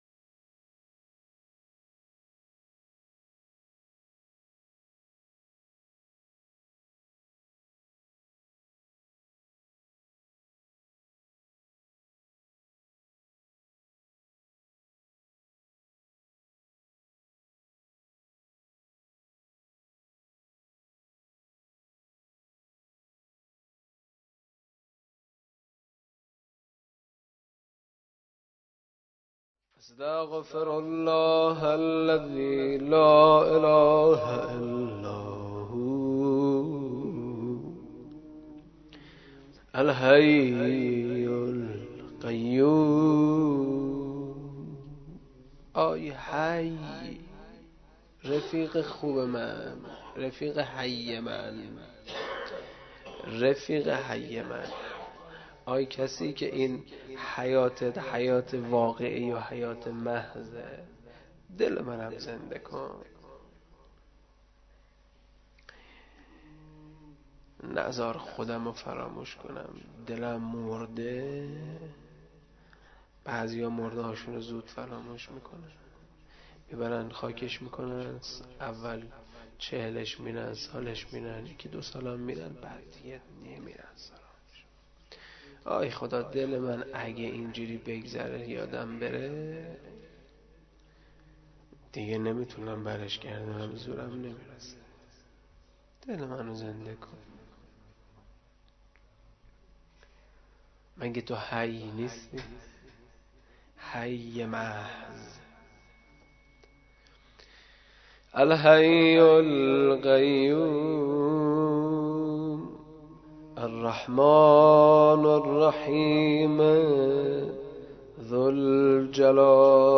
مراسم شب شهادت حضرت جواد الائمه (ع)؛ قسمت اول